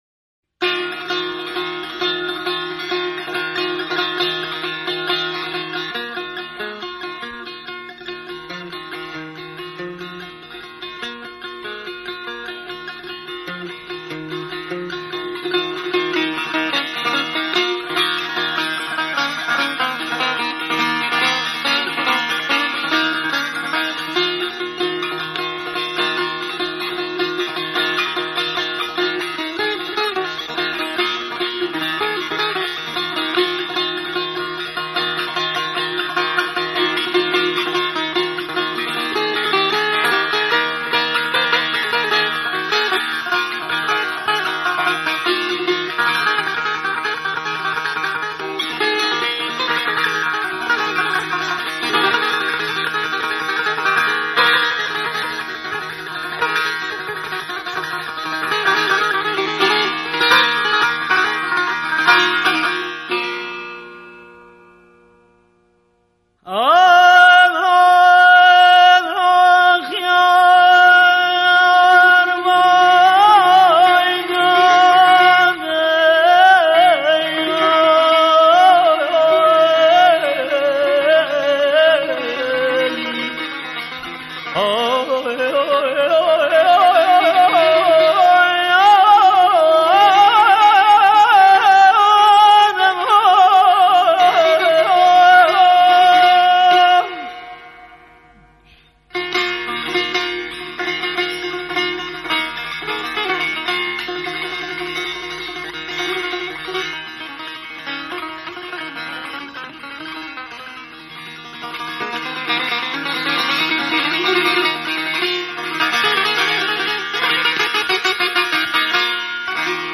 سه‌تار
audio file این آواز را می‌شنویم.